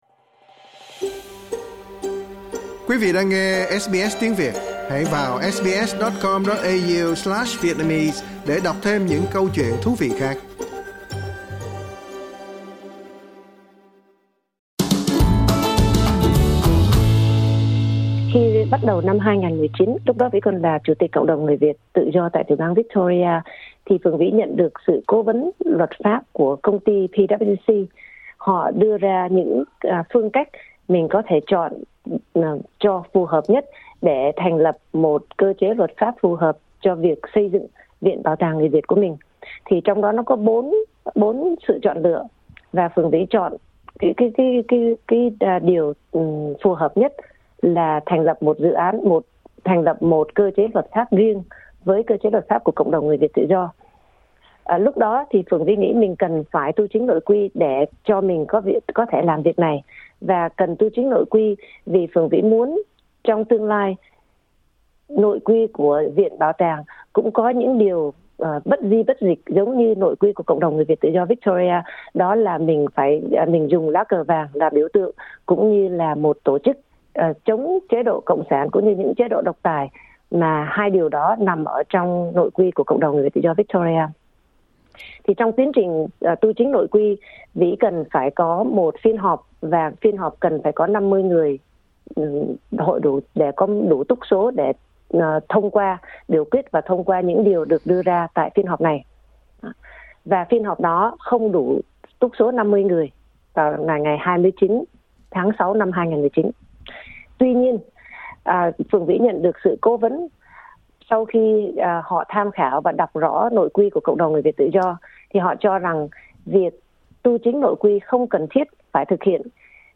Trò chuyện với SBS